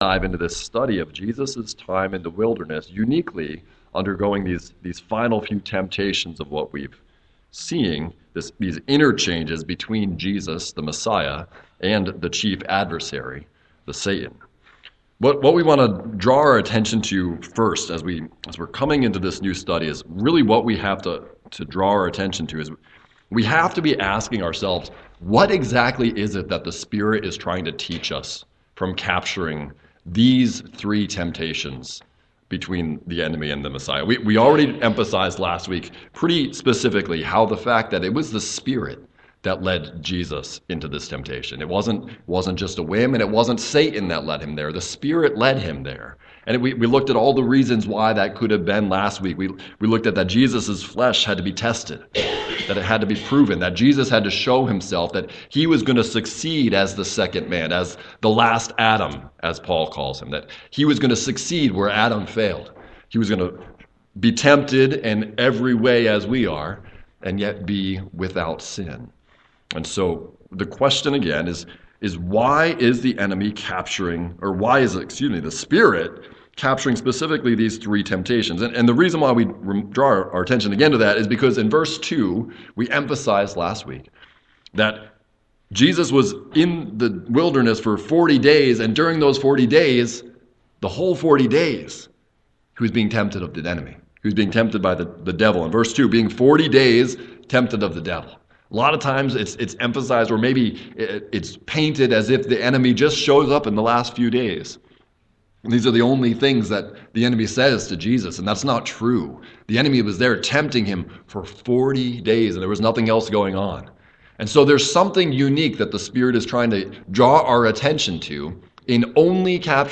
Luke: Wilderness Temptations #1 - Doubting God's Provision - Waynesboro Bible Church